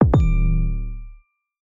알림음